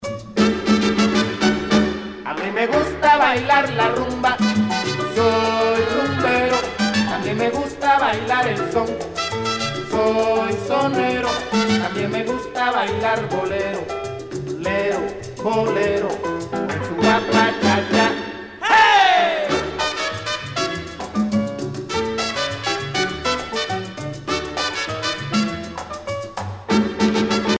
danse : guaracha